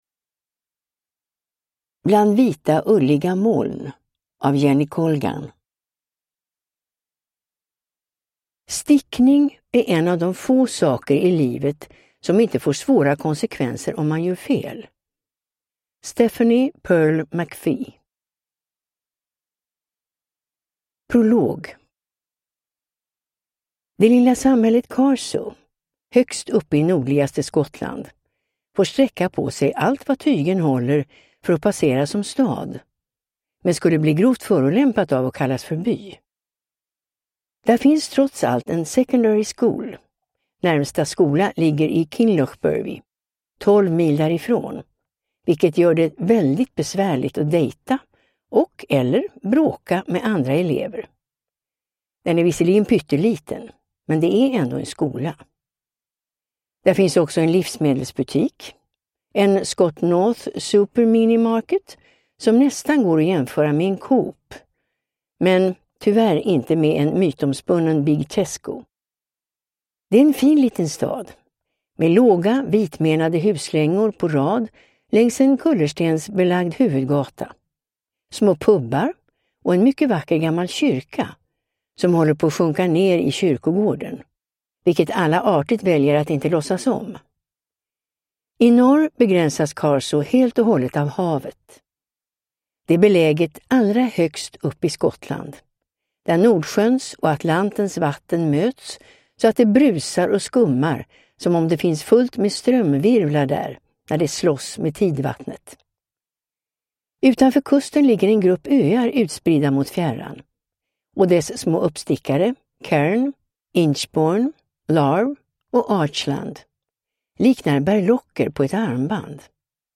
Bland vita ulliga moln – Ljudbok